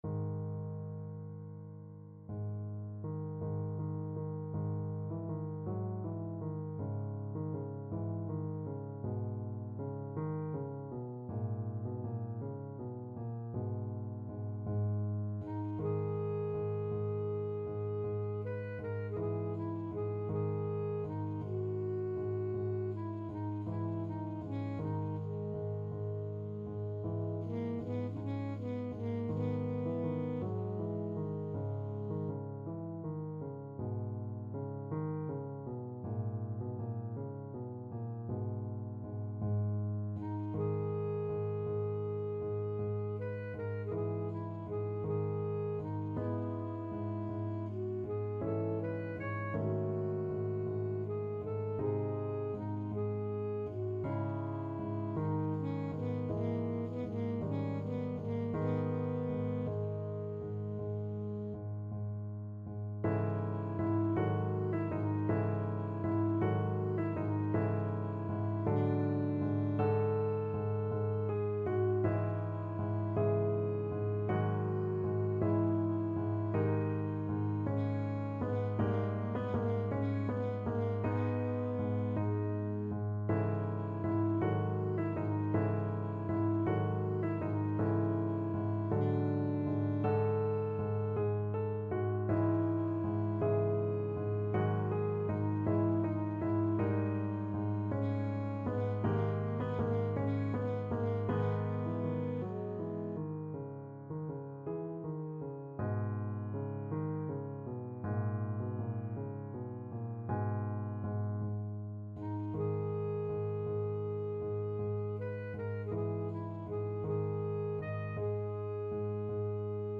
Alto Saxophone
Ab4-Ab6
6/8 (View more 6/8 Music)
Classical (View more Classical Saxophone Music)